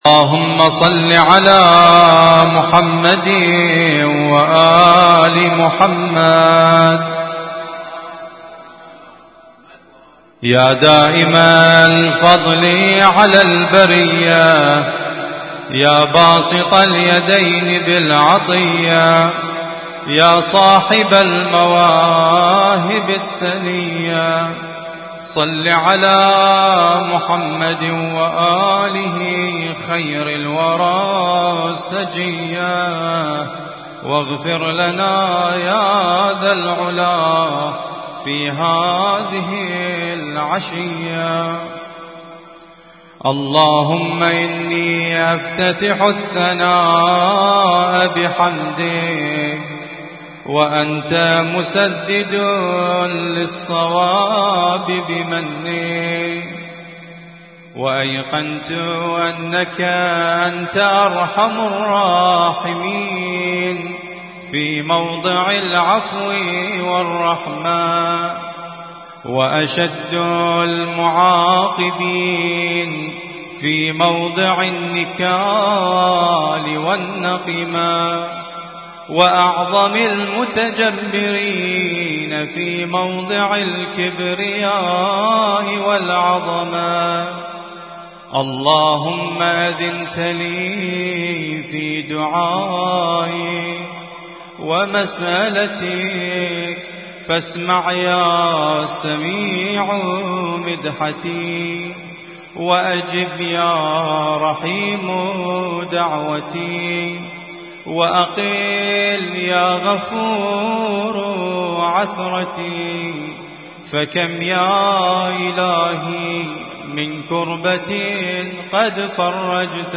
اسم التصنيف: المـكتبة الصــوتيه >> الادعية >> دعاء الافتتاح
البث المباشر / حسينية النور